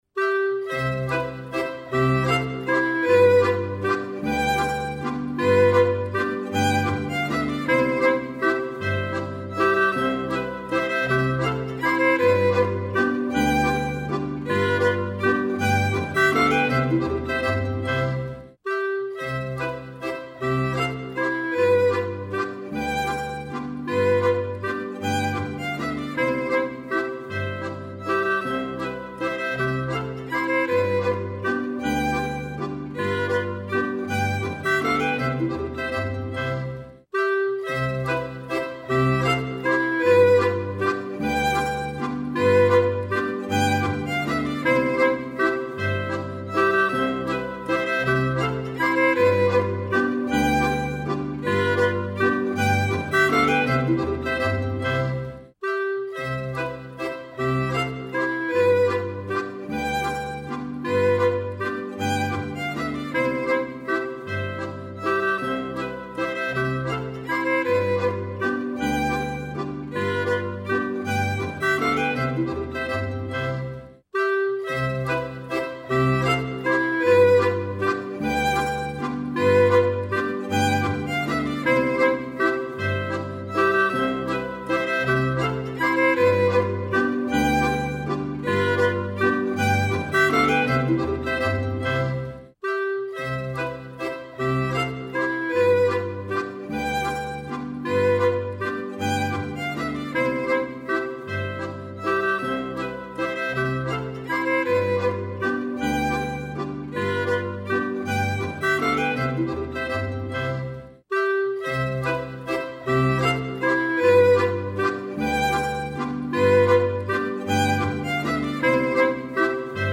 M/T: traditionell